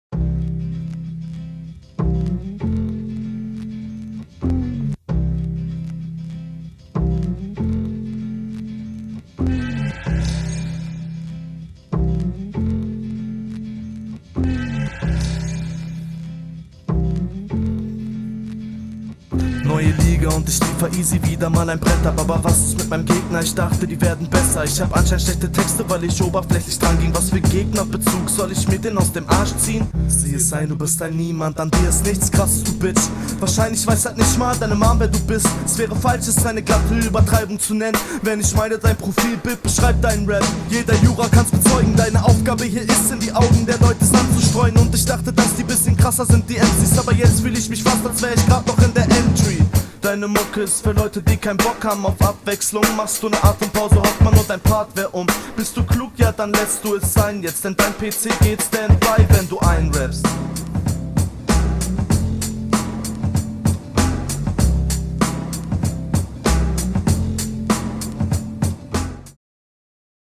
Battle Runden
Du past dich stimmlich gut der Atmosphäre des Beats …
Langes Intro bei der Rundenlänge.